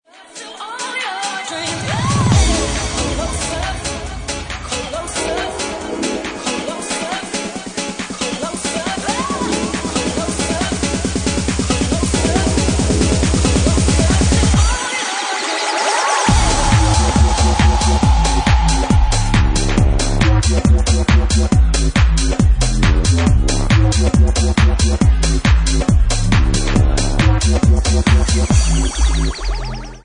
Bassline House at 139 bpm